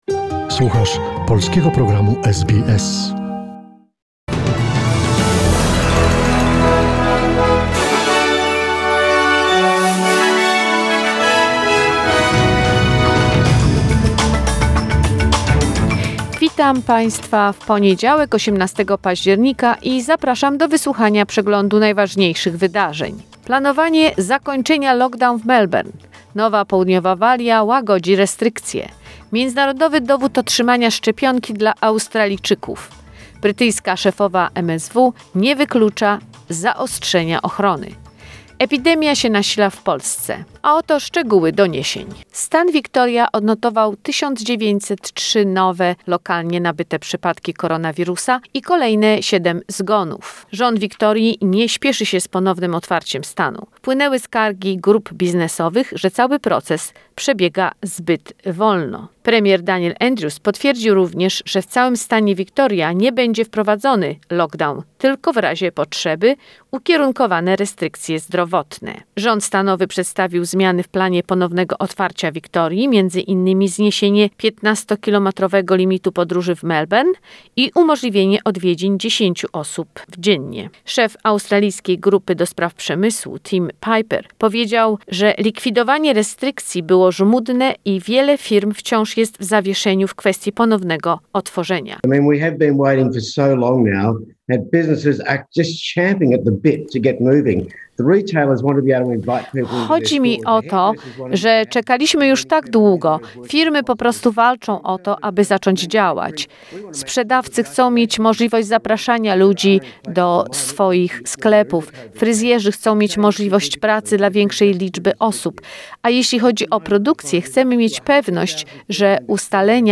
Wiadomości SBS, 18 października 2021